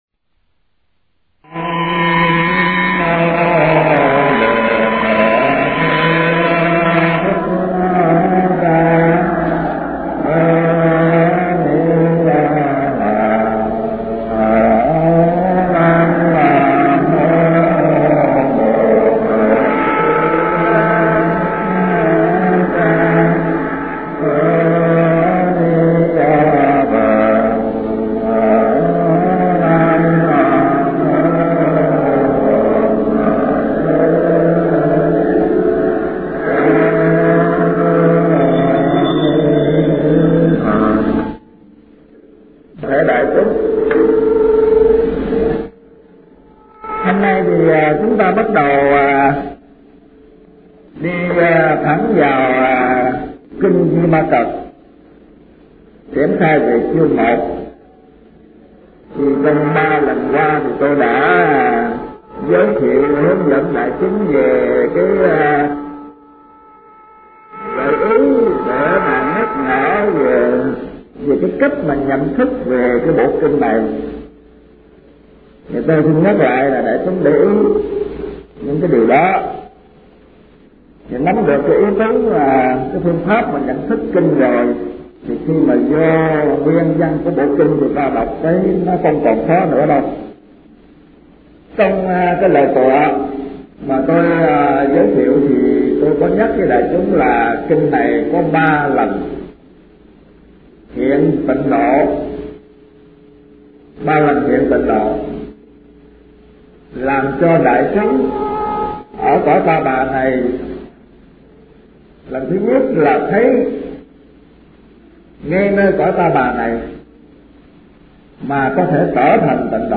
Kinh Giảng Kinh Duy Ma Cật